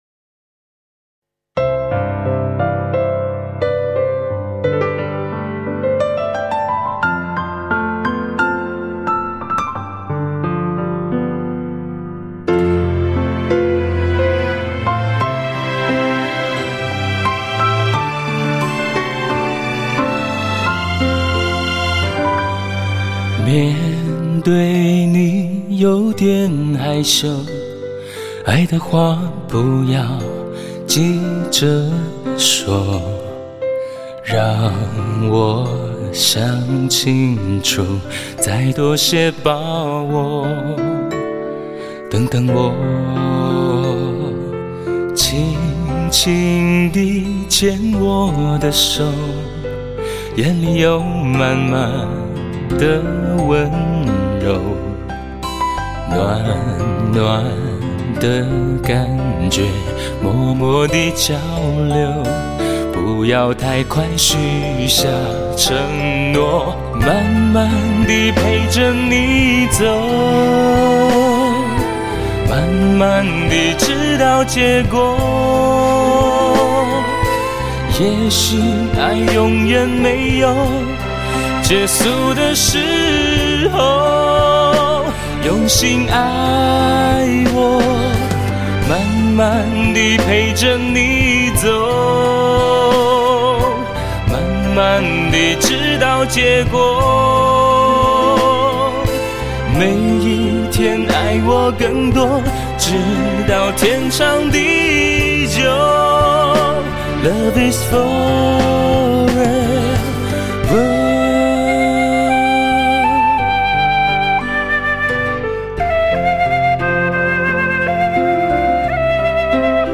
音乐灵感的碰撞，纯发烧的配器，出彩的人声和乐器表现，
结合高超的录音技术，